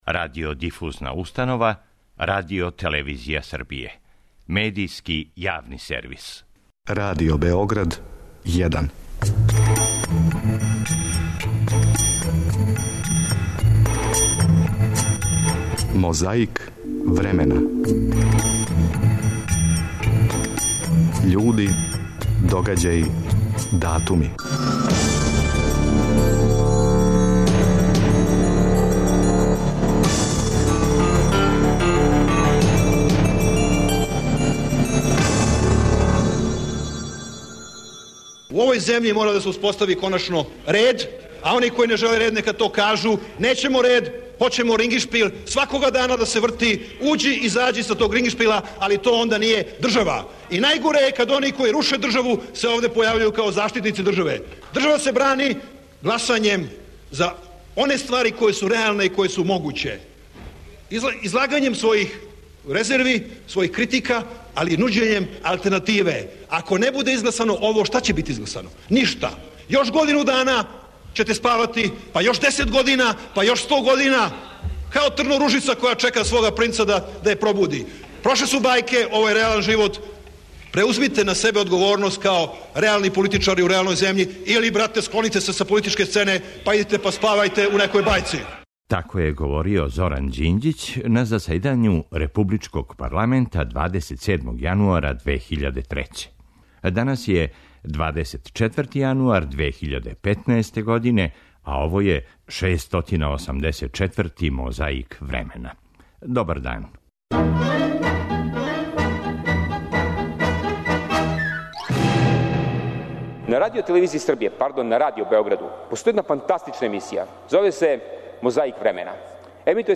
Звучна коцкица враћа нас у 2003. годину да се не заборави како је говорио Зоран Ђинђић на заседању републичког парламента...
Подсећа на прошлост (културну, историјску, политичку, спортску и сваку другу) уз помоћ материјала из Тонског архива, Документације и библиотеке Радио Београда.